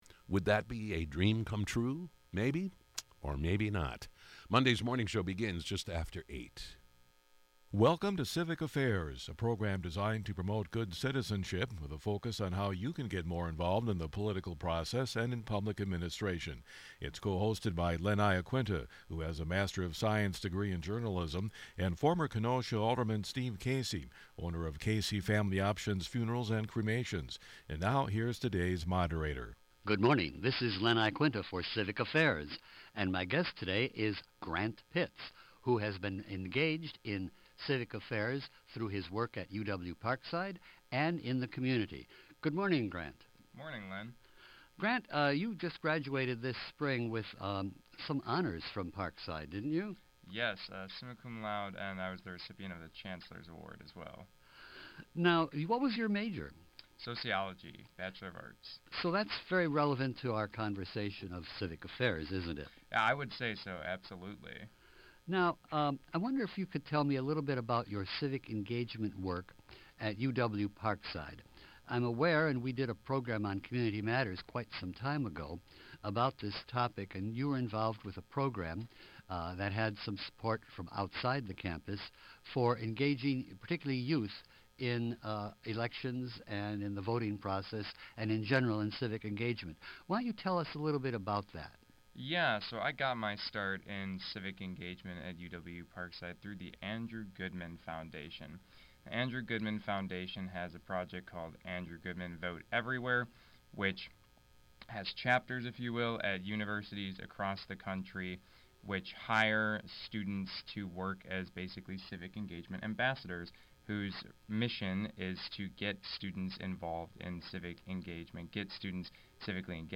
The discussion highlighted innovative strategies to motivate community members to participate actively in the democratic process, ensuring that everyone’s voice is heard. Listen to Interview